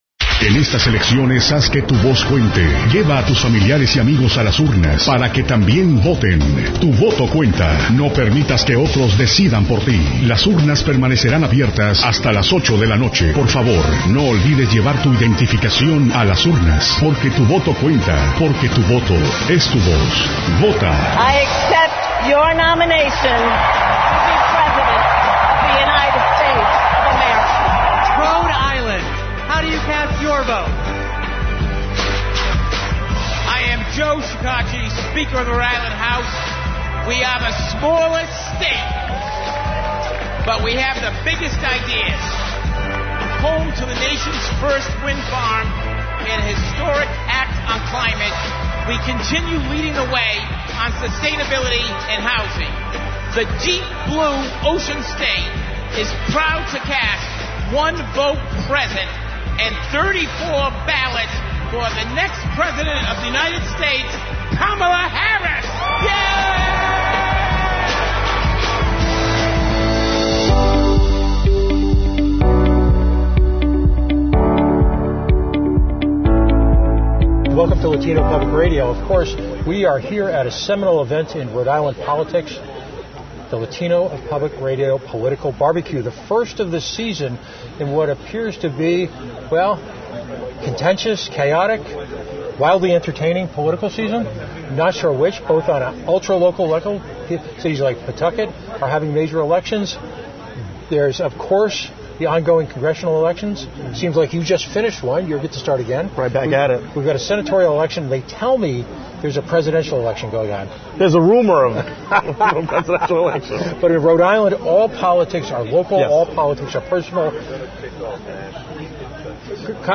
Congressman Gabe Amo at LPR’s Political BBQ 2024